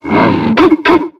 Cri de Mastouffe dans Pokémon X et Y.